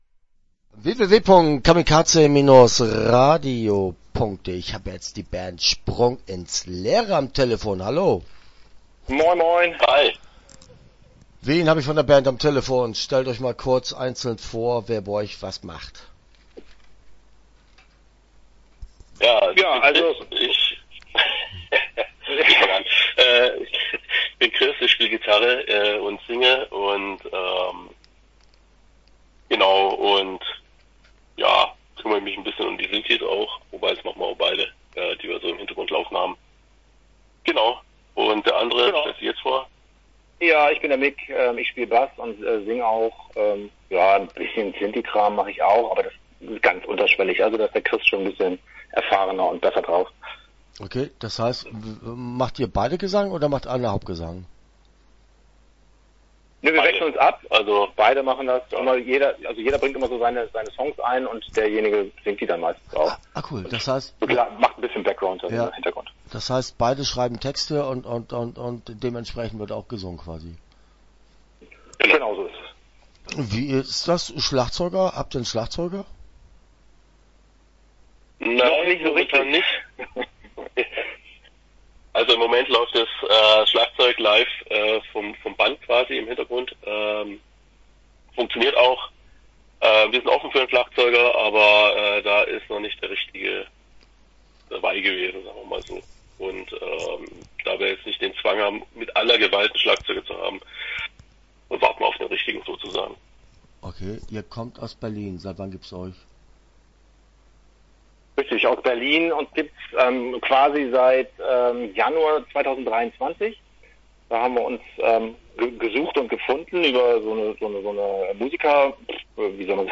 Start » Interviews » Sprung ins Leere